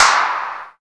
VEC3 Claps 039.wav